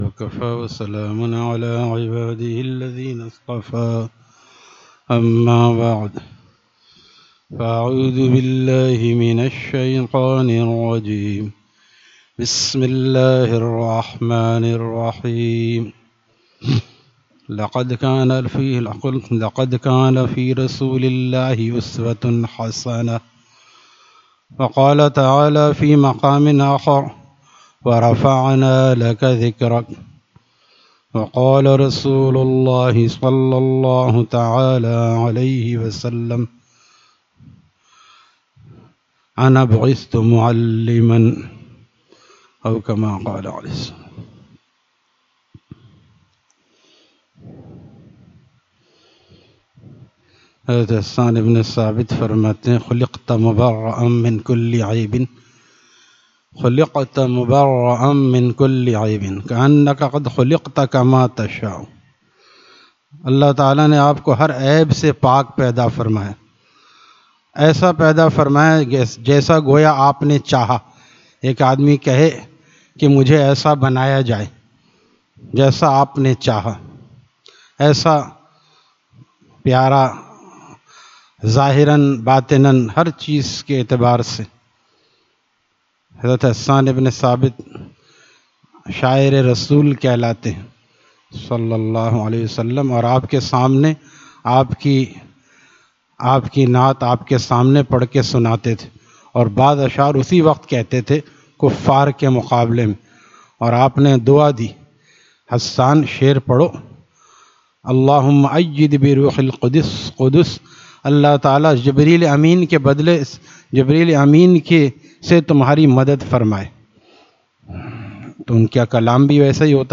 Friday Markazi Bayan at Jama Masjid Gulzar e Muhammadi, Khanqah Gulzar e Akhter, Sec 4D, Surjani Town